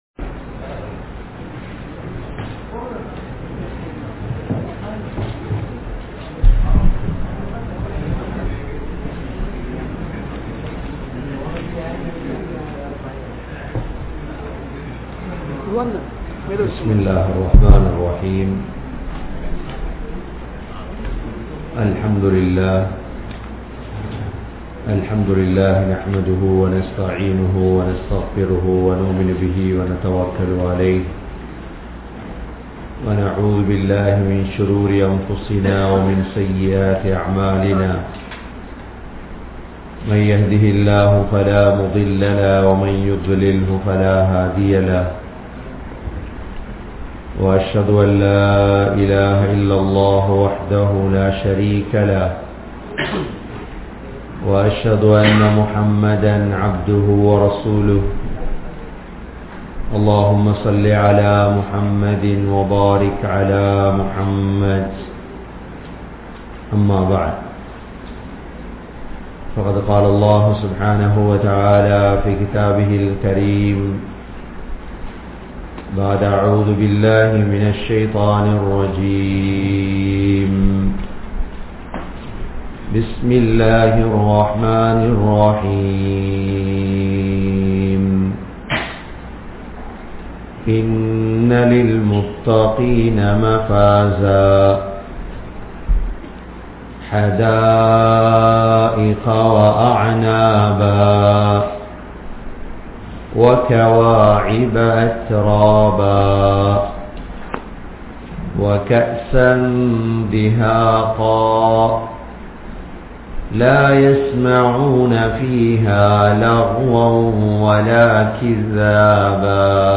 Qiyaamath Naalin Kodooram (கியாமத் நாளின் கொடூரம்) | Audio Bayans | All Ceylon Muslim Youth Community | Addalaichenai
Canada, Toronto, Thaqwa Masjidh